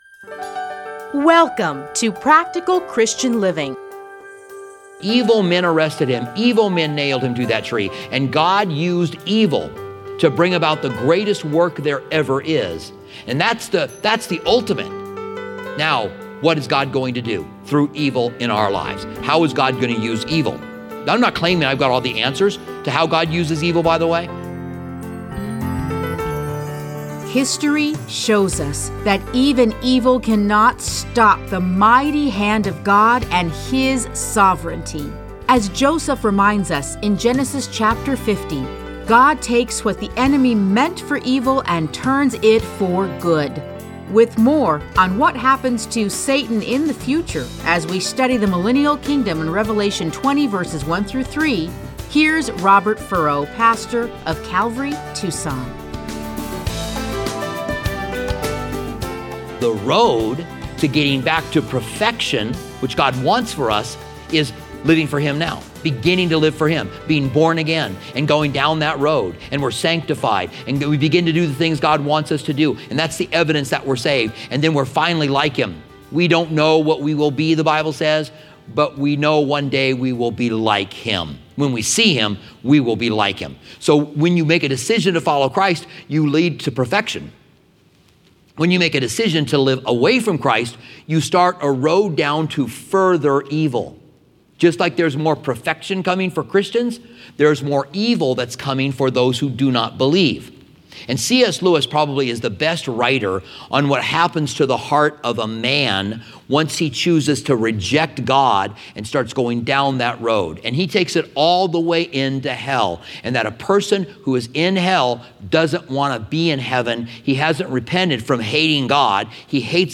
Listen to a teaching from Revelation 20:1-3.